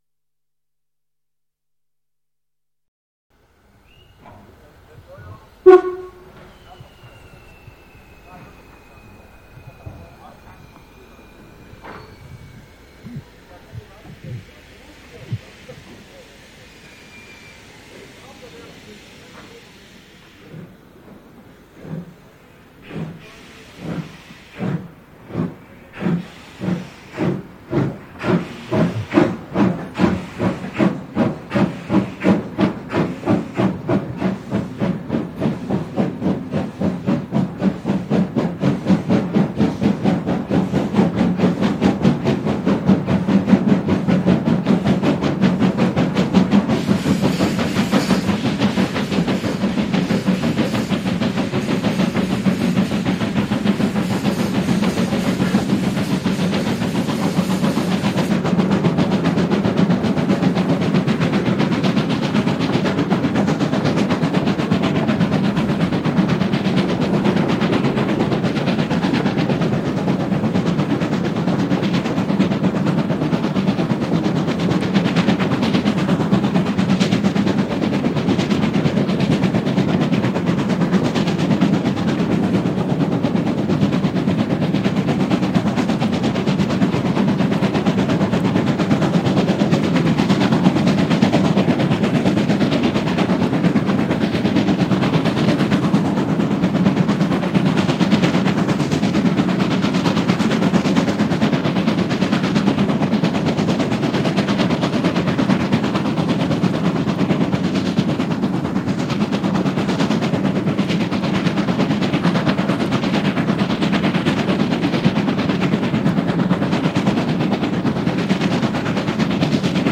Interessant und auf jeden Fall Wert für Soundaufnahmen waren die Beschleunigungen dahinter direkt „in den Berg“. In diesem Fall hielt der Zug sogar an!
Mitfahrt:   hinter 41 1231  im D 902 Richtung Meiningen, Ausfahrt Gräfenroda bis hinter Dörrberg, am 26.04.1991.   Hier anhören: